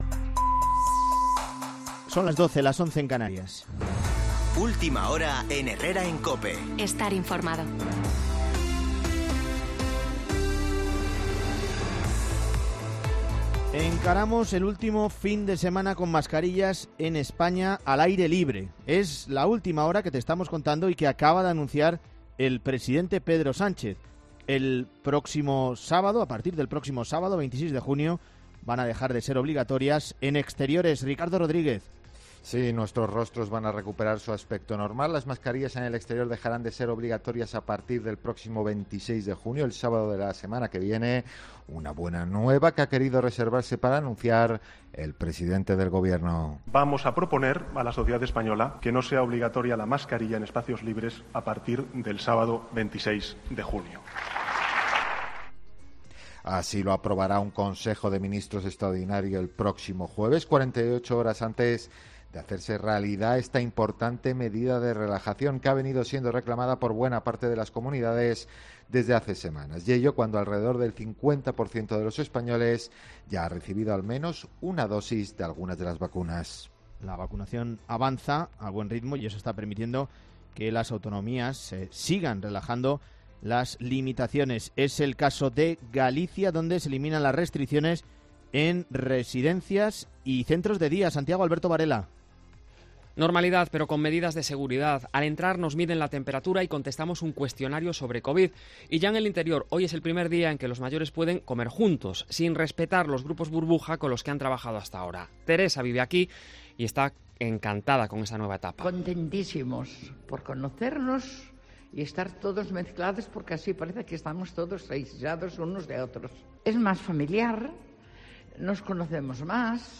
Boletín de noticias COPE del 18 de junio de 2021 a las 12.00 horas